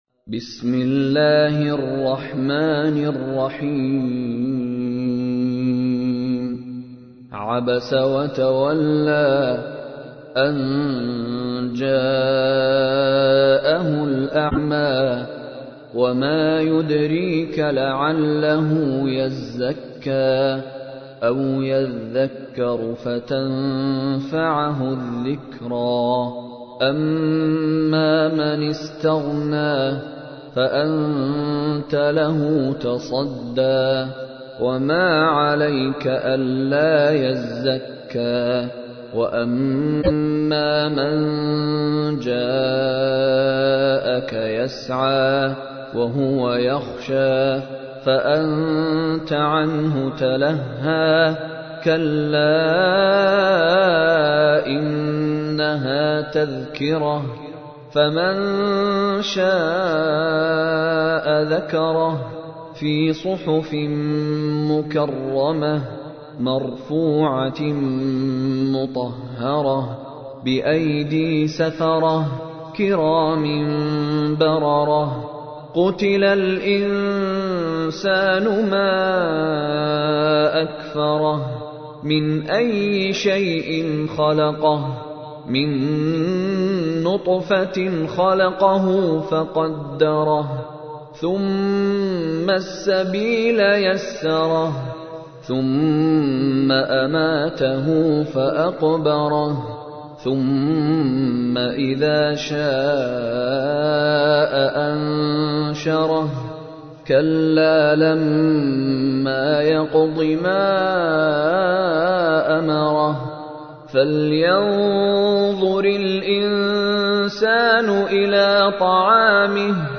تحميل : 80. سورة عبس / القارئ مشاري راشد العفاسي / القرآن الكريم / موقع يا حسين